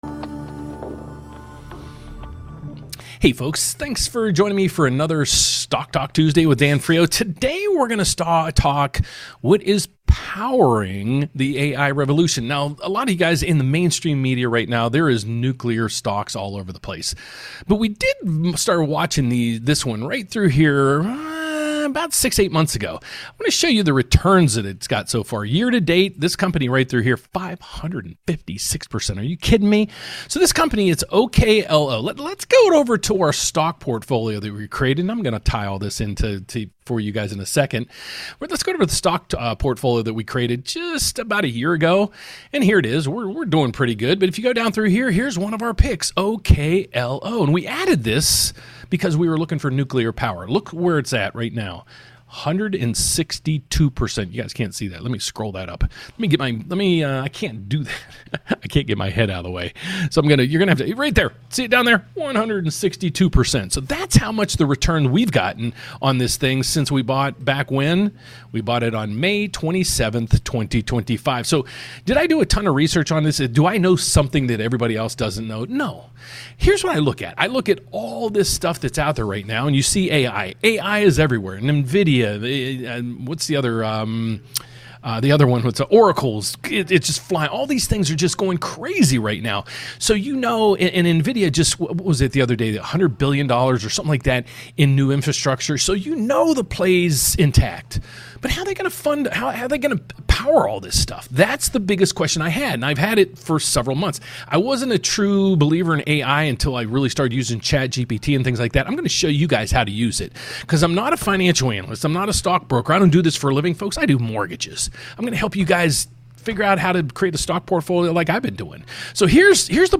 🚀 LIVE EVENT | What Stocks Are POWERING the AI Revolution (And How to Invest Smart)